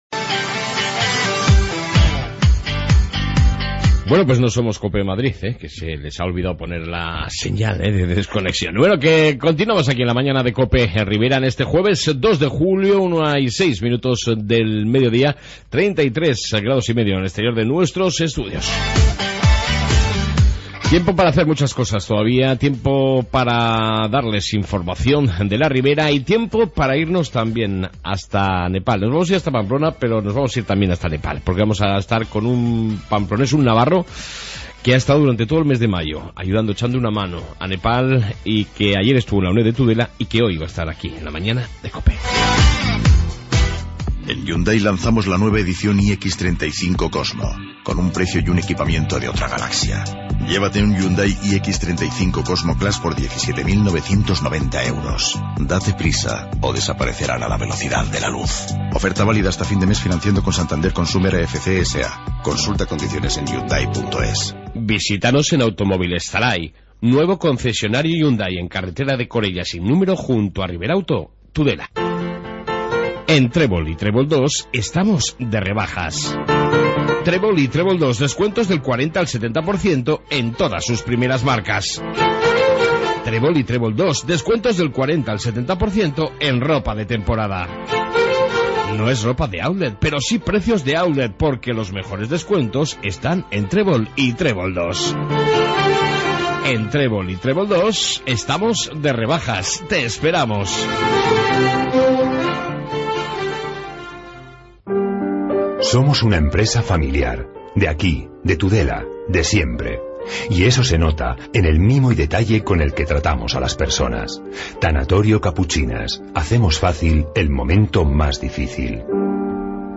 Informativo ribero